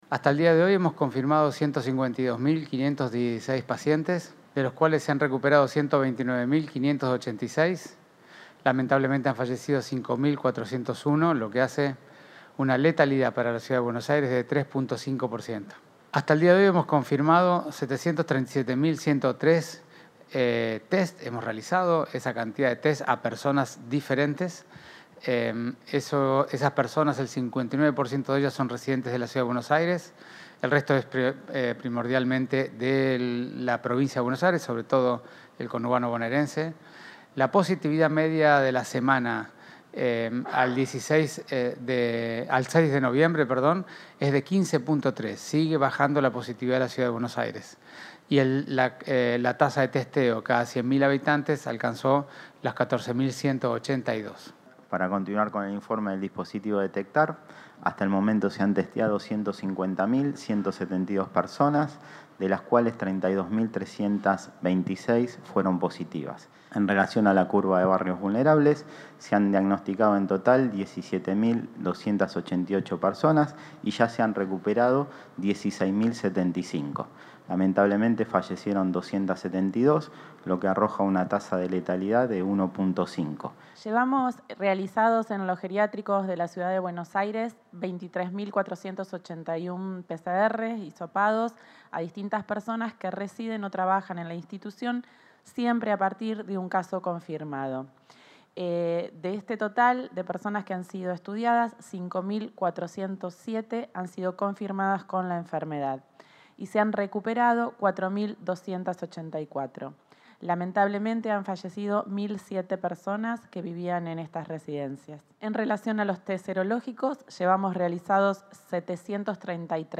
Participaron el ministro Fernán Quirós, el subsecretario de Atención Primaria, Gabriel Battistella, y la directora general de Planificación Operativa, Paula Zingoni.